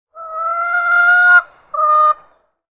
Cluck Chicken Sound Effect
Description: Cluck chicken sound effect. Hen clucking sound on a poultry farm in the countryside. Chicken noises. Farm animal sounds.
Cluck-chicken-sound-effect.mp3